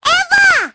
Cri d'Évoli dans Pokémon Épée et Bouclier.